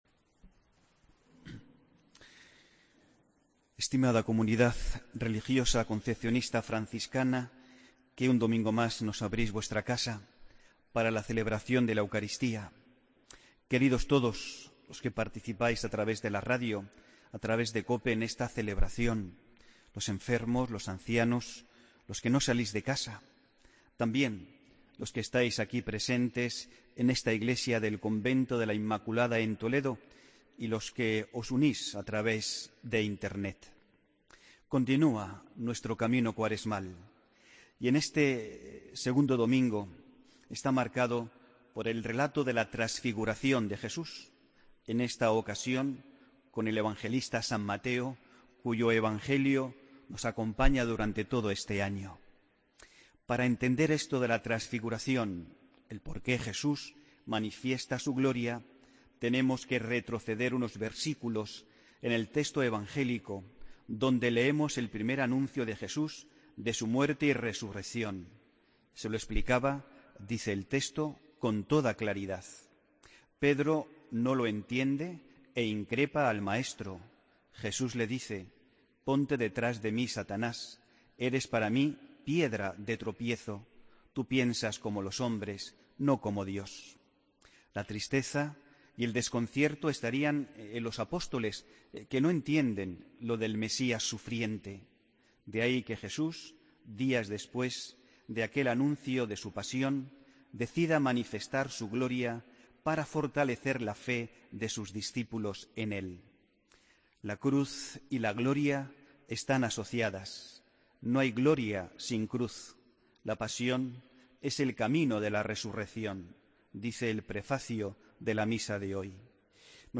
Homilía del domingo 12 de marzo de 2017